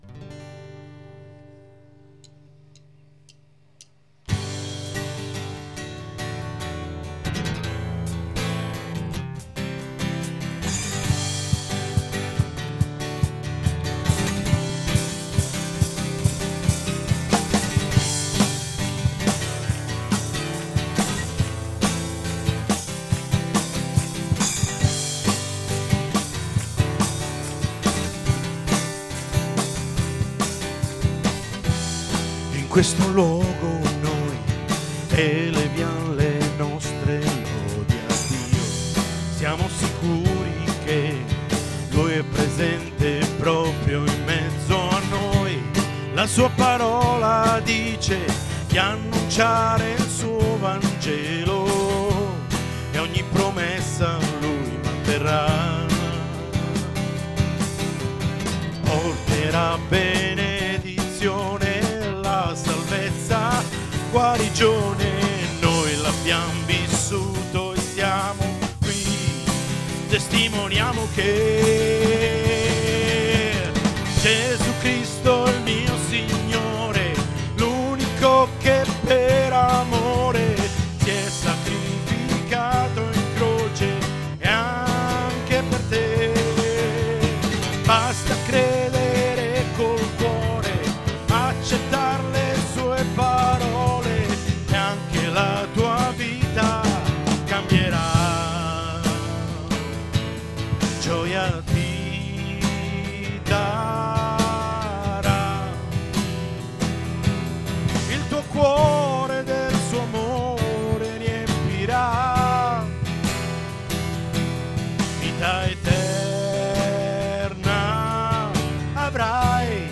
Testimonianza